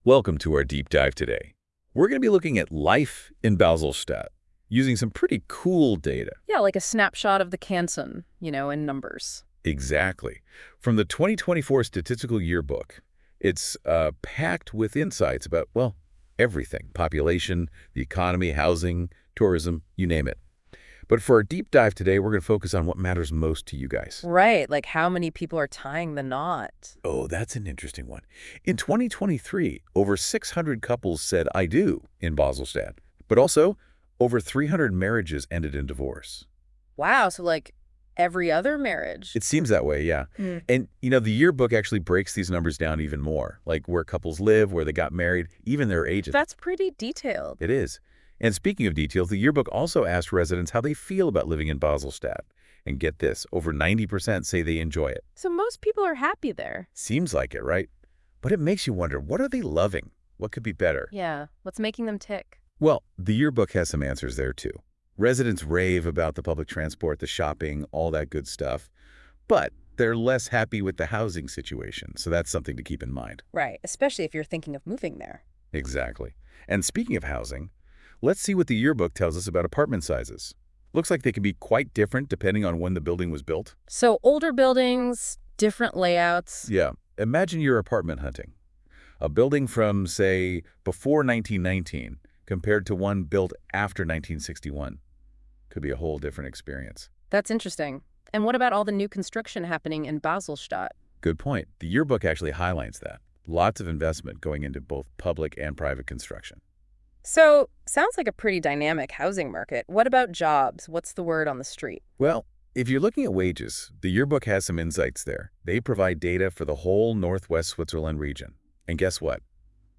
KI-generierter Podcast anhören (engl.)